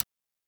Click (18).wav